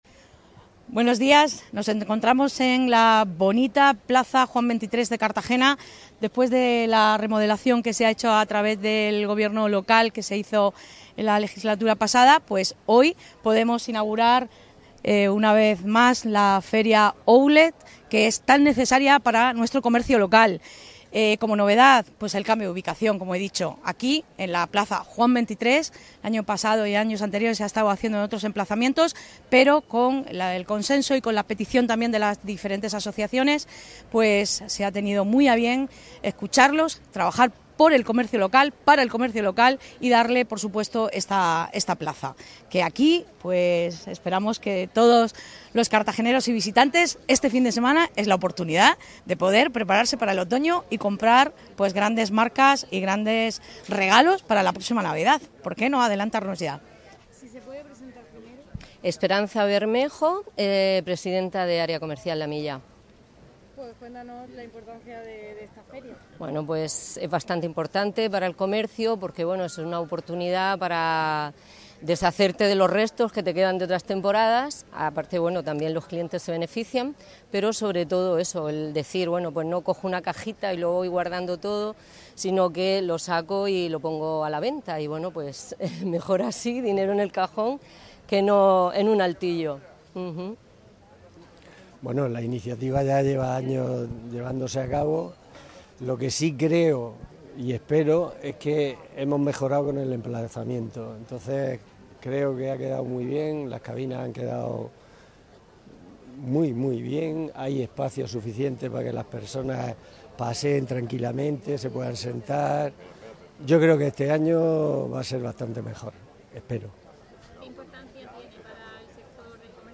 Enlace a Declaraciones la edil Belén Romero